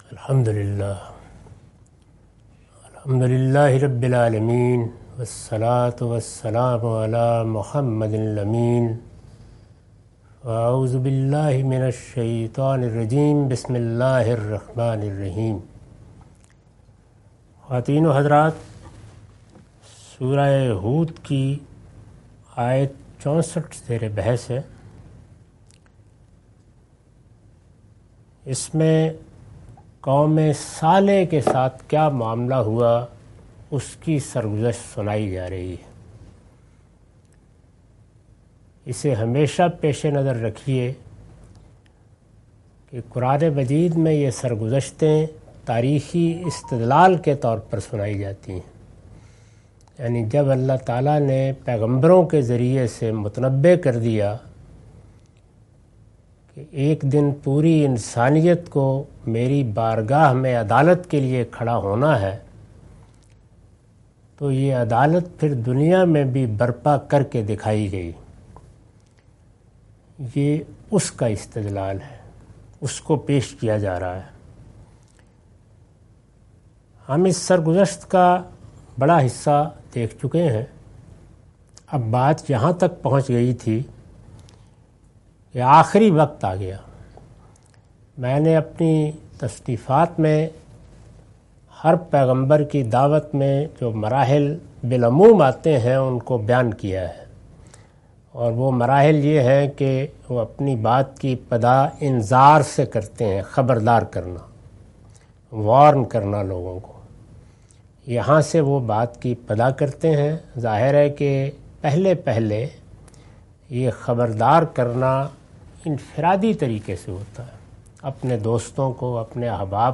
Surah Hud- A lecture of Tafseer-ul-Quran – Al-Bayan by Javed Ahmad Ghamidi. Commentary and explanation of verses 64-70.